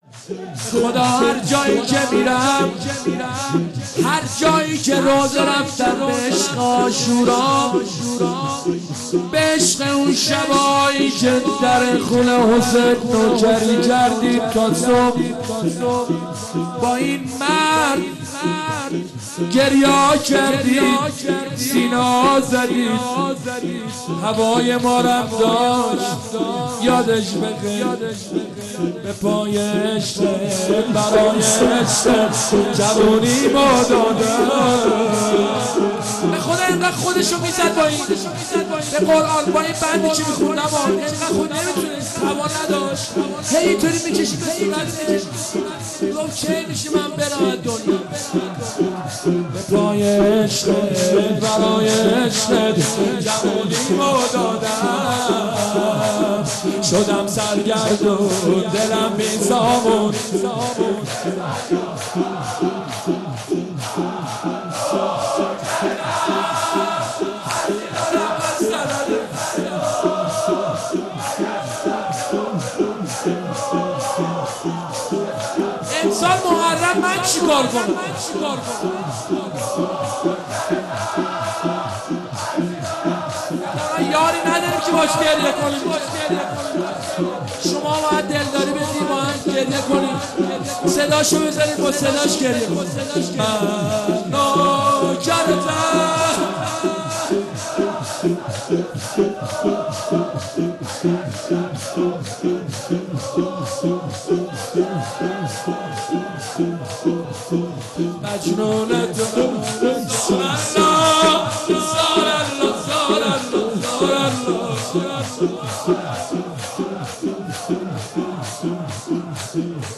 با اینکه پیکر پسرش بوریا شود روضه محمود کریمی